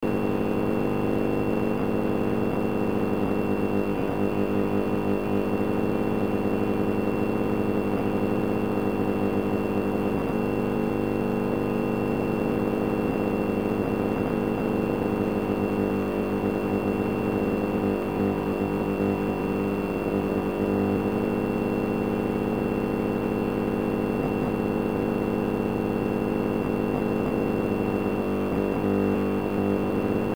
в). РВ-166 -- Иркутск, 40 кВт, 200 кГц.
Пример сигнала.